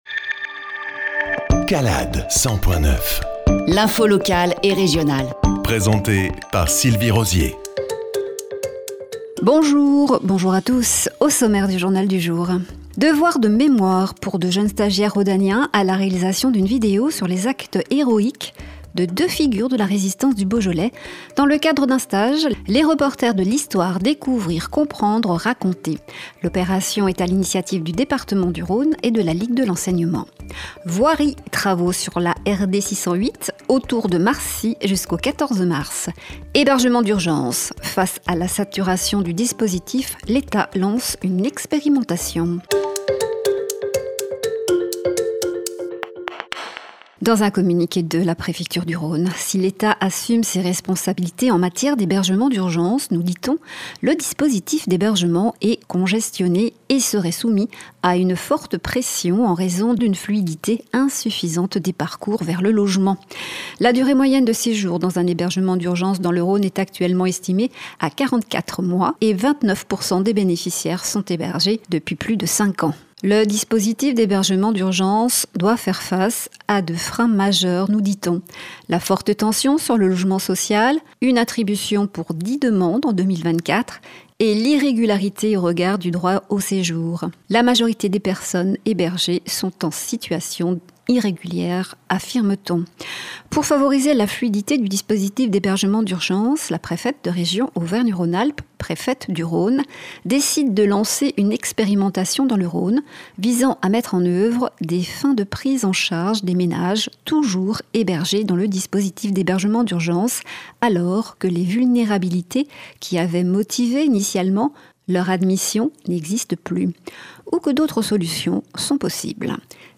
Journal du 03-03-25